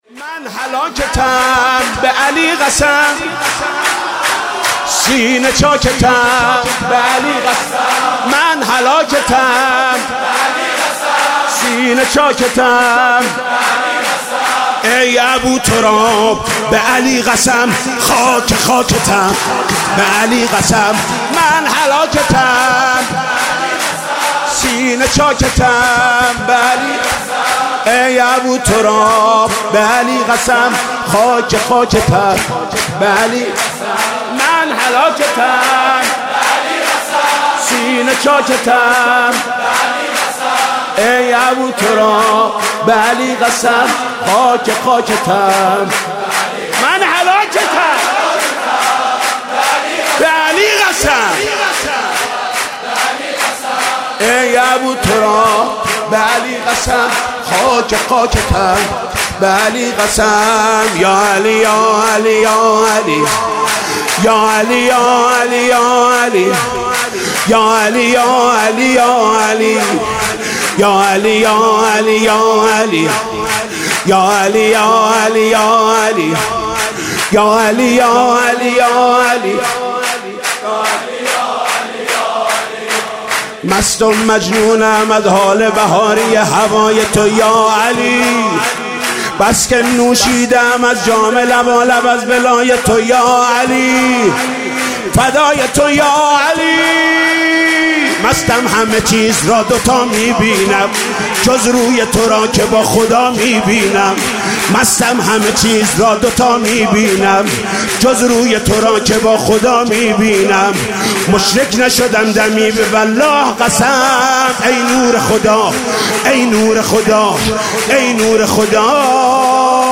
به مناسبت فرارسیدن سالروز ولادت با سعادت امیرالمومنین و روز پدر مولودی‌خوانی محمود کریمی را می‌شنوید.